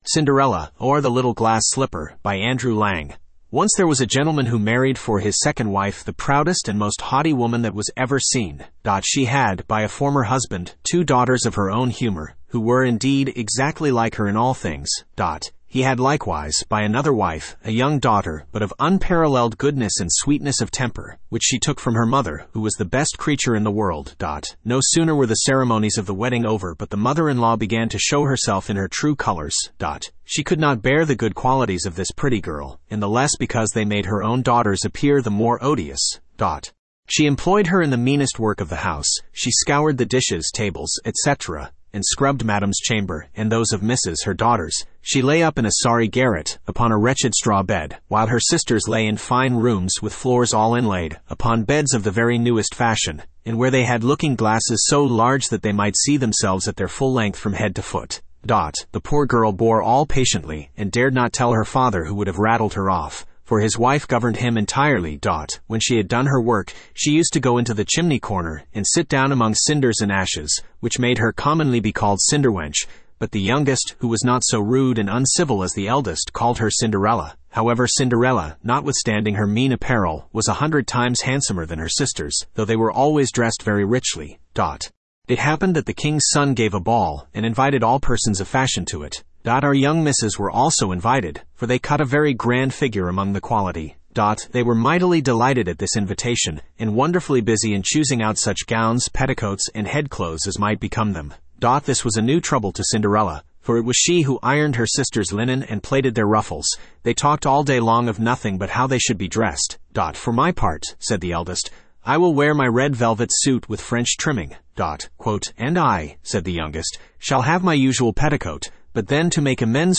Spoken Ink « The Blue Fairy Book Cinderella, Or The Little Glass Slipper Studio (Male) Download MP3 Once there was a gentleman who married, for his second wife, the proudest and most haughty woman that was ever seen.